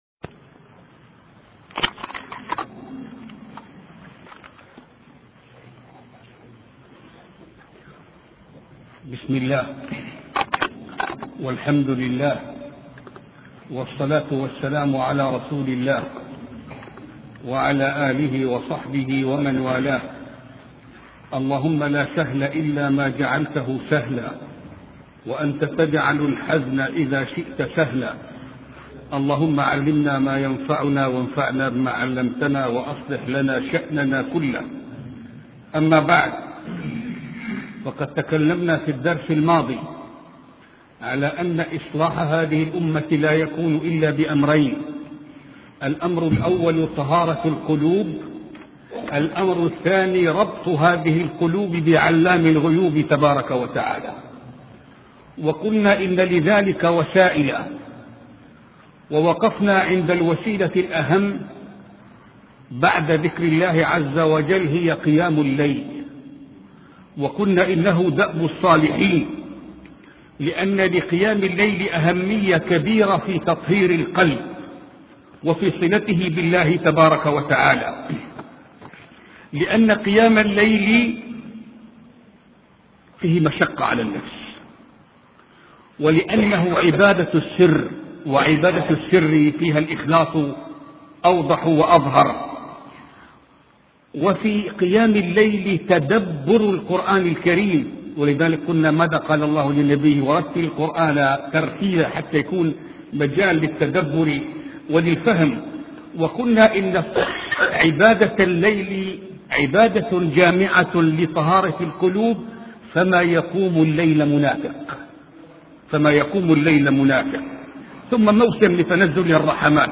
سلسلة محاطرات في قيام الليل